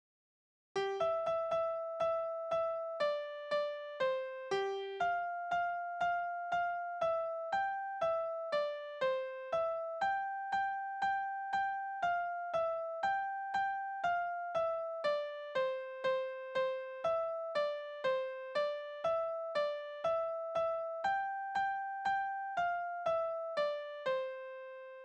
Balladen
Tonart: C-Dur
Taktart: 2/4
Tonumfang: Oktave
Besetzung: instrumental